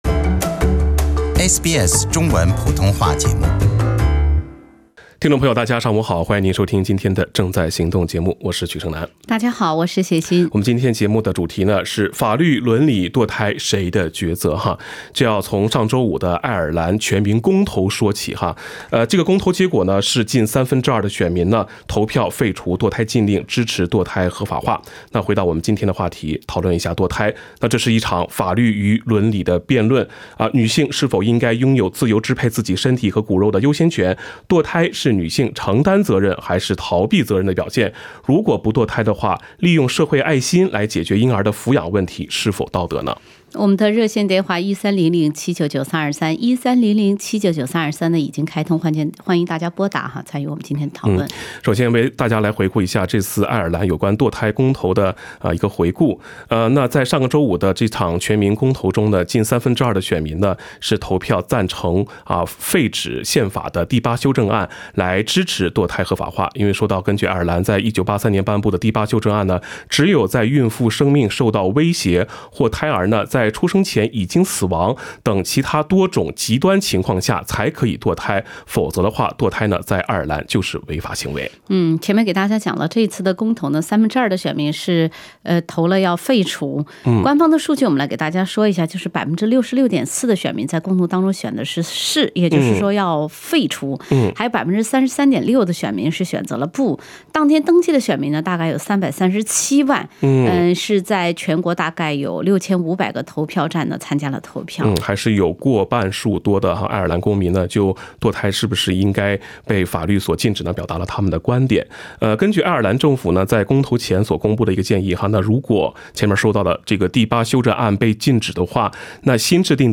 以上为听众言论节选，不代表本台立场） 时政热线节目《正在行动》逢周三上午8点半至9点播出。